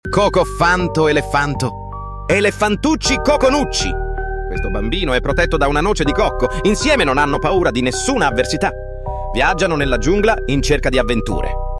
cocofanto elefanto Meme Sound Effect